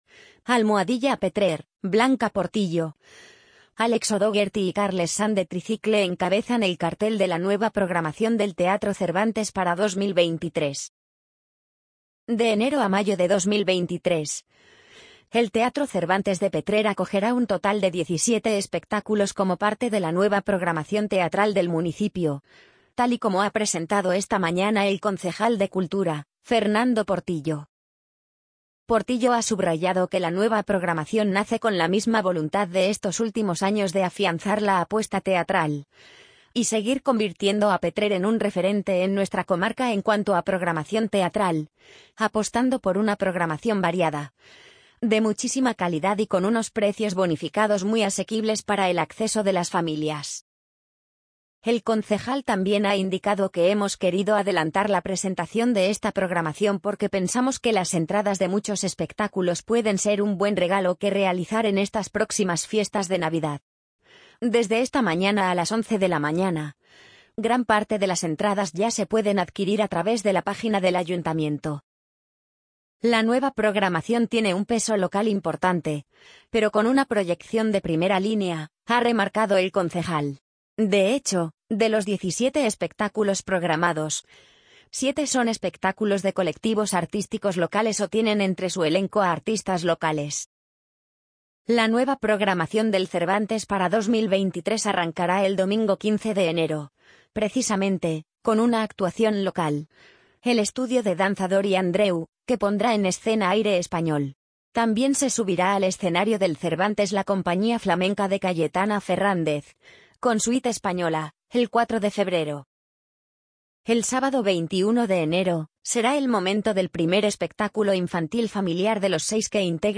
amazon_polly_62708.mp3